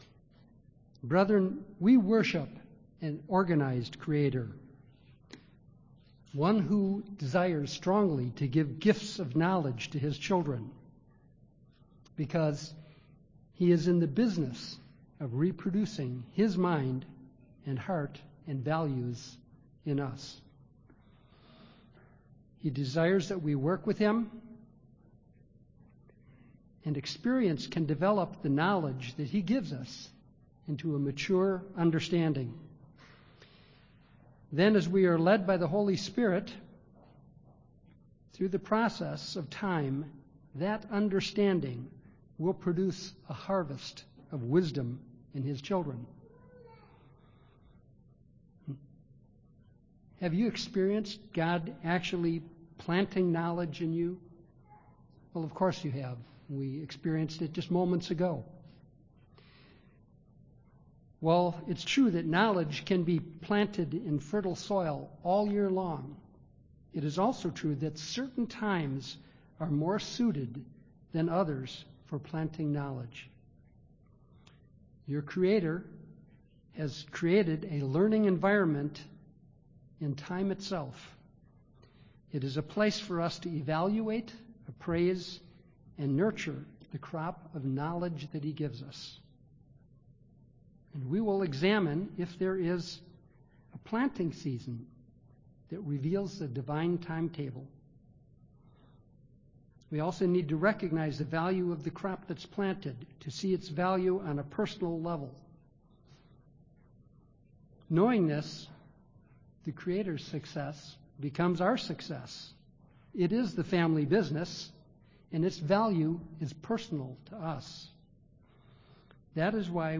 Sermon
Given in Milwaukee, WI